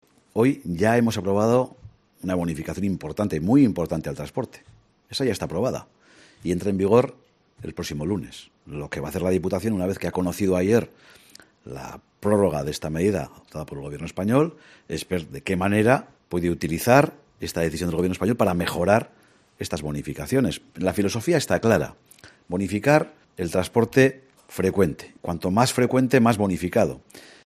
Ramiro González, diputado general de Álava sobre los descuentos del transporte público en 2024